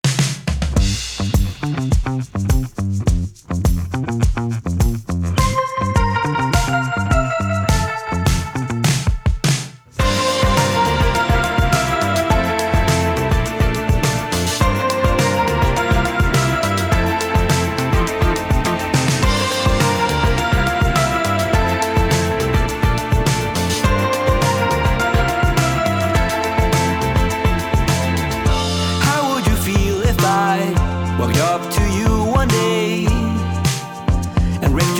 Жанр: Иностранный рок / Рок / Инди / Альтернатива